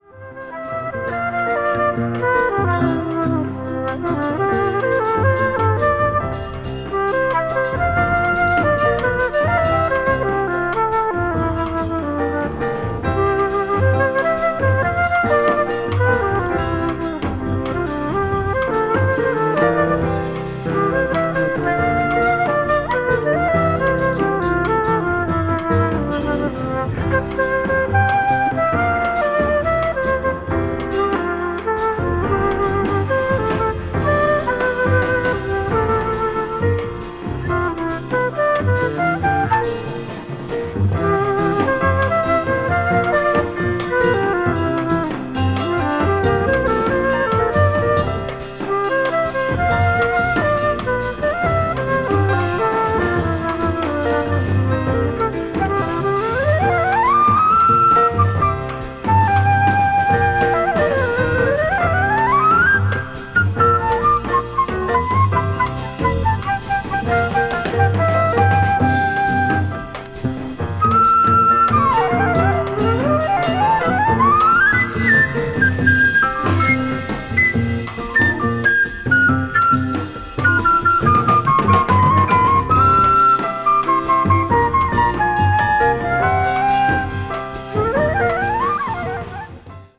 contemporary jazz   ethnic jazz   post bop